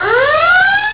HORN.WAV